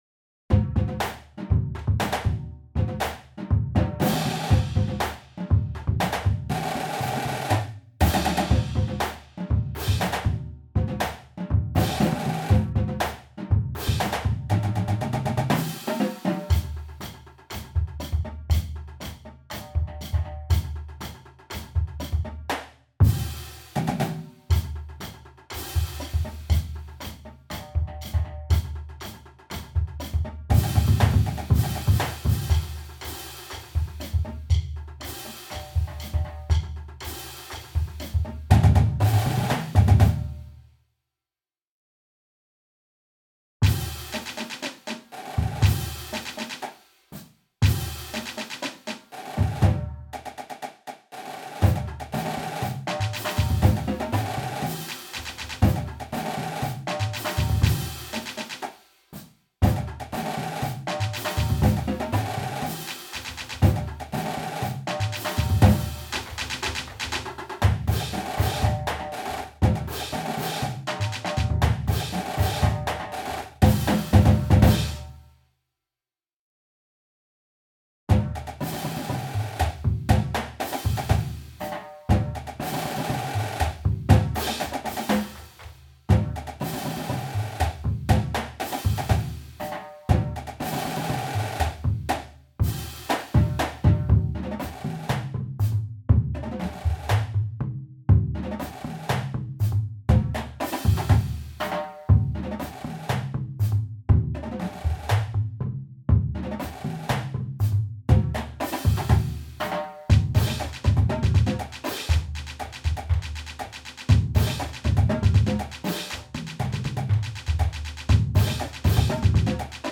Voicing: Percussion Cadence